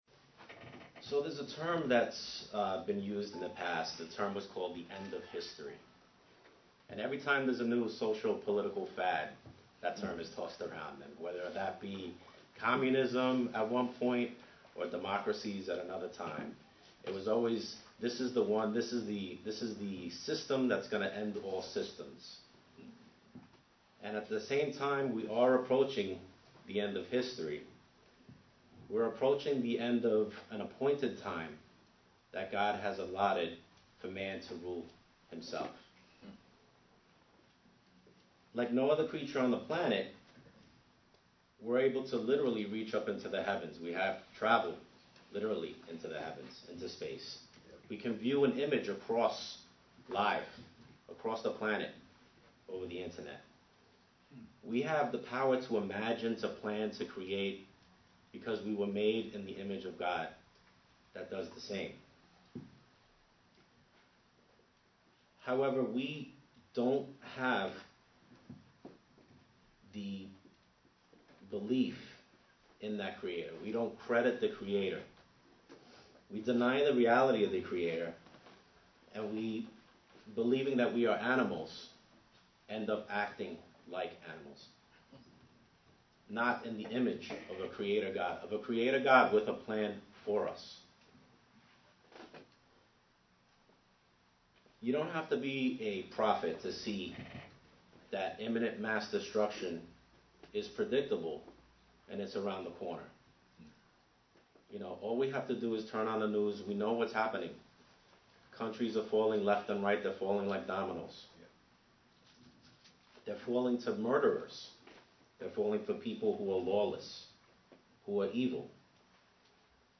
Given in New York City, NY
UCG Sermon Studying the bible?